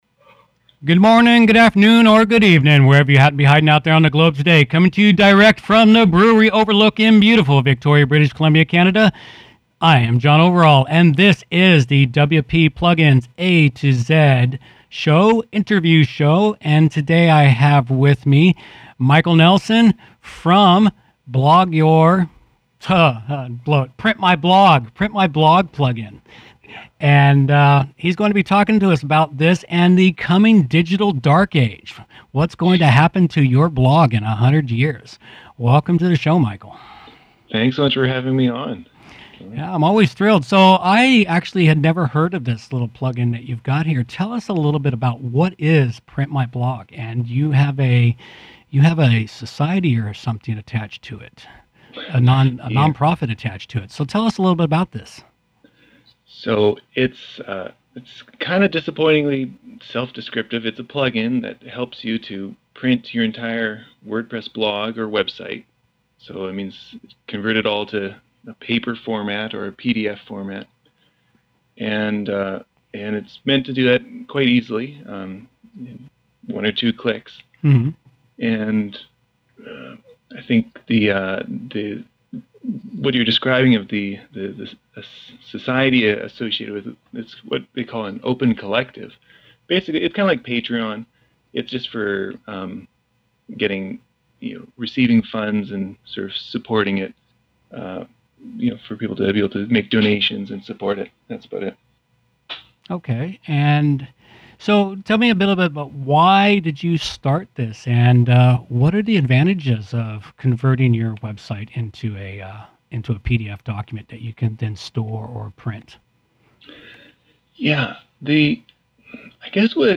Interview 35